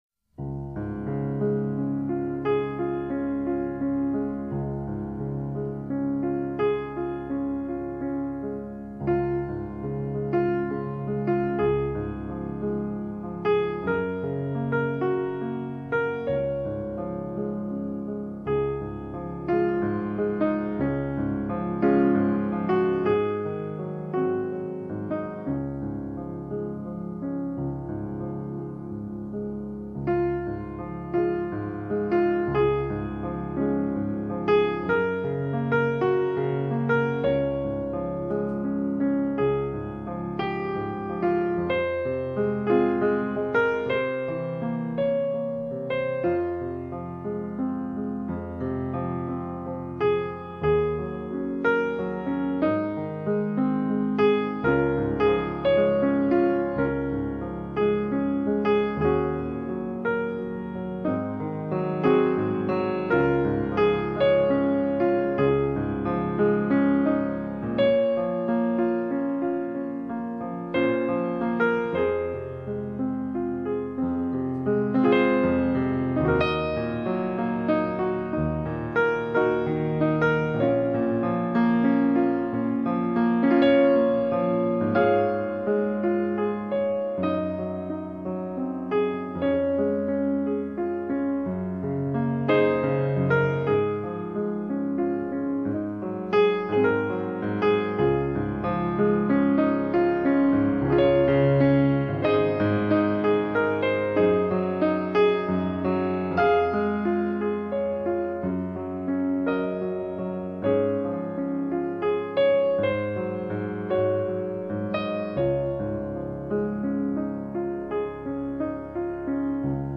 流派: 轻音乐
SOLO PIANO PERFORMANCES OF TRADITIONAL CHRISTMAS SONGS.